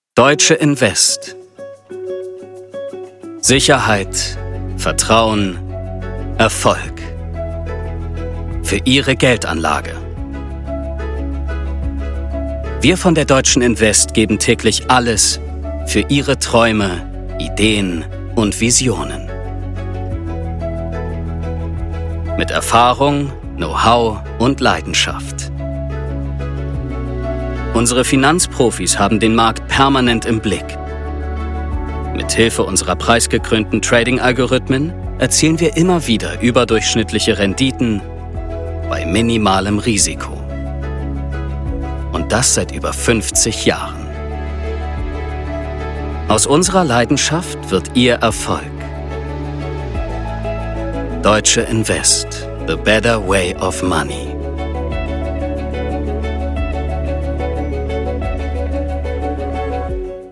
Sprecher, Synchronsprecher